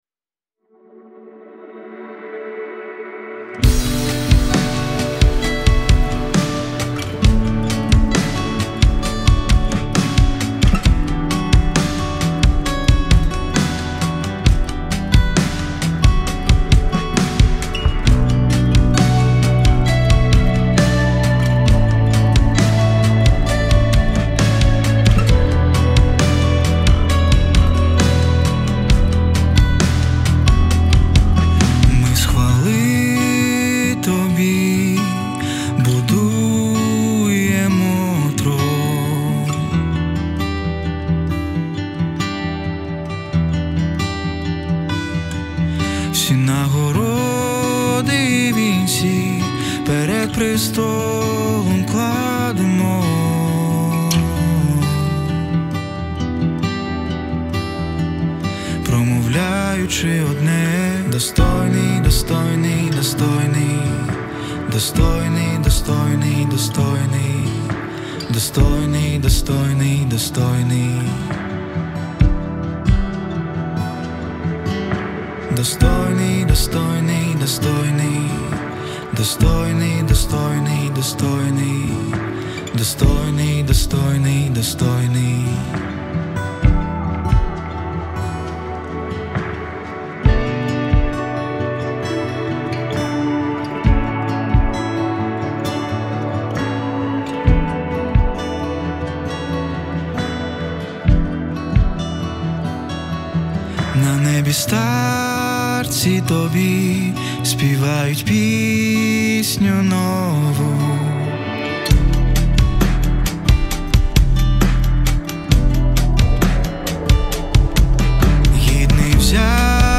615 просмотров 166 прослушиваний 34 скачивания BPM: 130